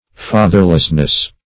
Fatherlessness \Fa"ther*less*ness\, n. The state of being without a father.